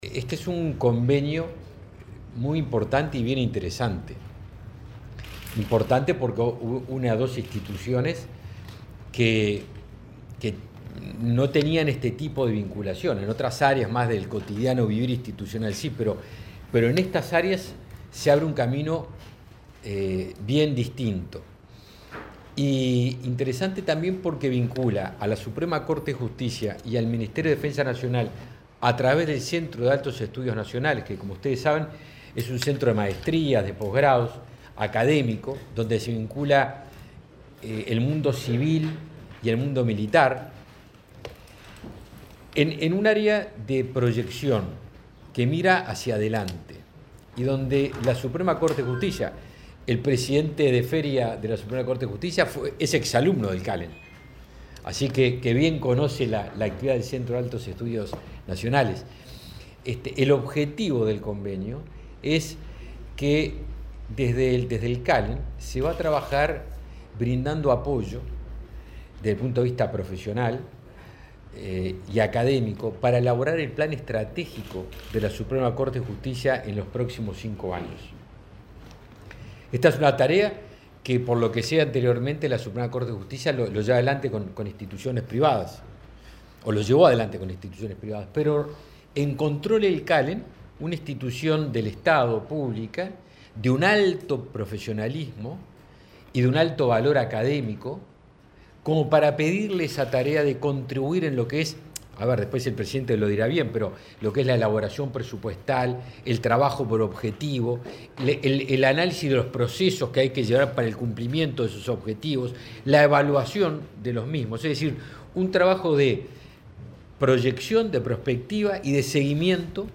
Palabras de autoridades en convenio entre Ministerio de Defensa y SCJ